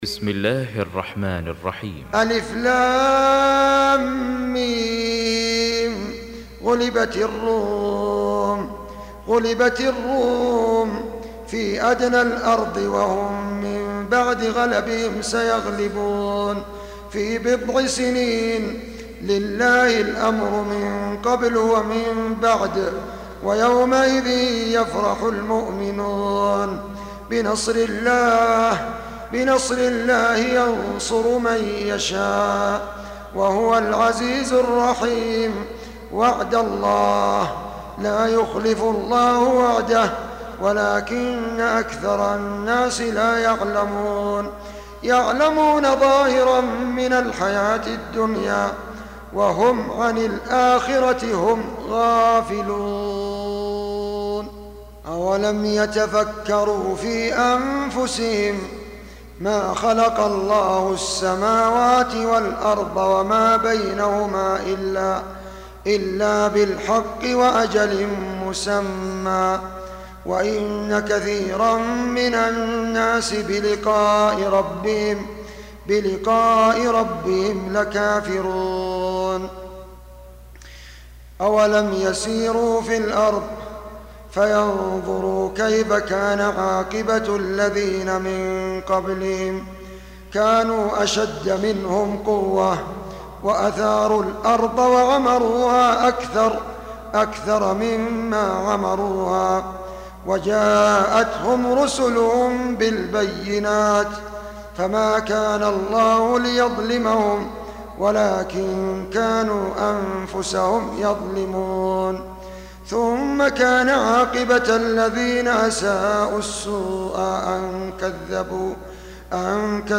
Audio Quran Tarteel Recitation
Surah Sequence تتابع السورة Download Surah حمّل السورة Reciting Murattalah Audio for 30. Surah Ar�R�m سورة الرّوم N.B *Surah Includes Al-Basmalah Reciters Sequents تتابع التلاوات Reciters Repeats تكرار التلاوات